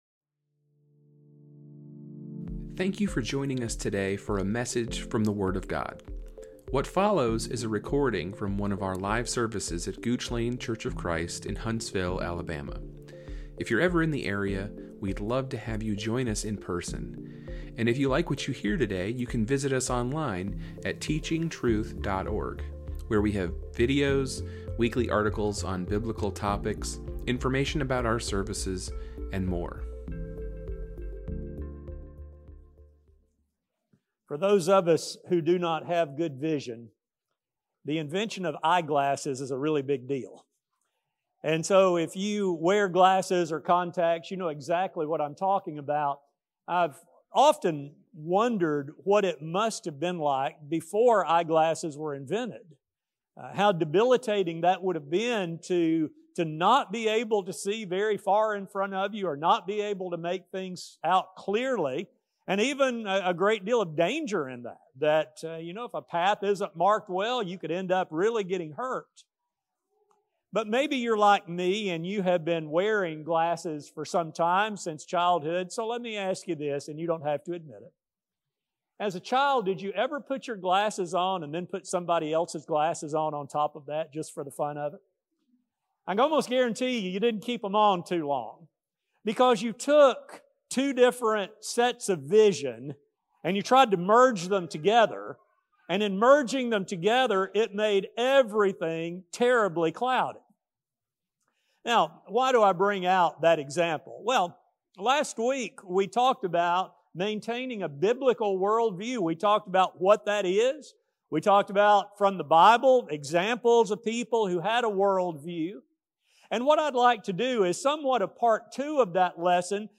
This study will demonstrate how first-century Christians faced this struggle and how Christians of the modern era must maintain vigilance not to inadvertently intermix the gospel message of the cross with inferior things. A sermon